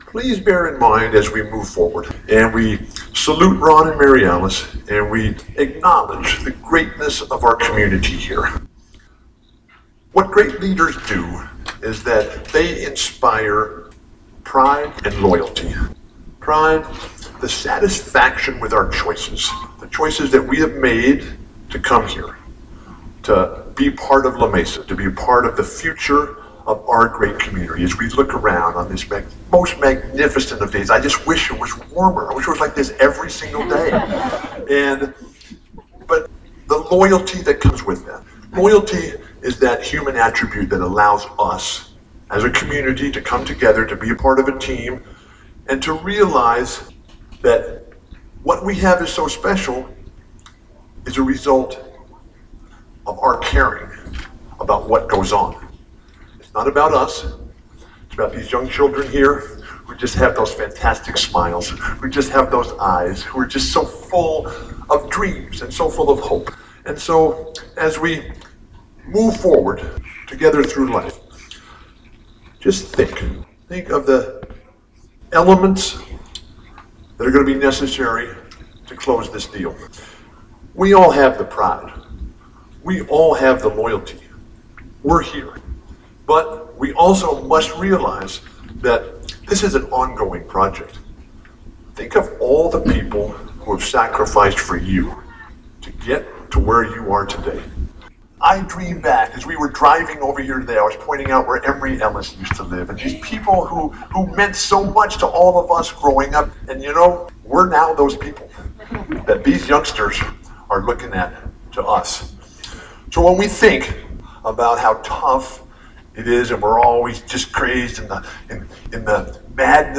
audio of Walton's speech at the La Mesa Boys and Girls Club in 2014.